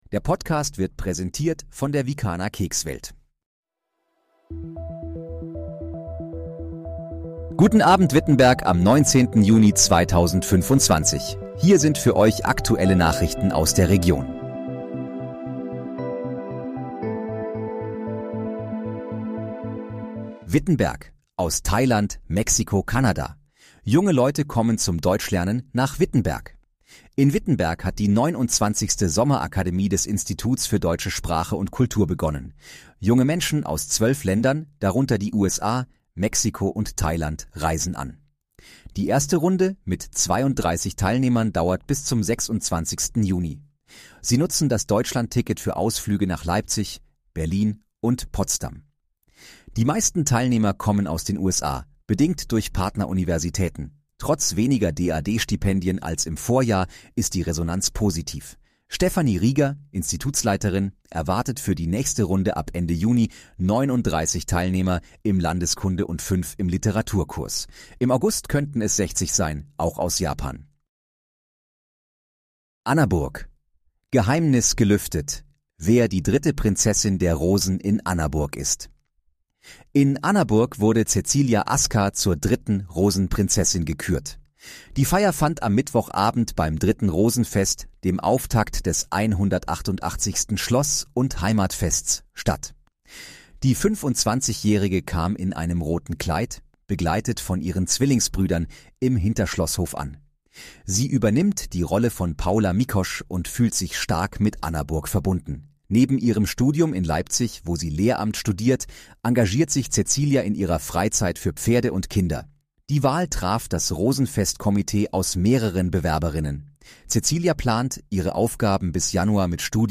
Guten Abend, Wittenberg: Aktuelle Nachrichten vom 19.06.2025, erstellt mit KI-Unterstützung
Nachrichten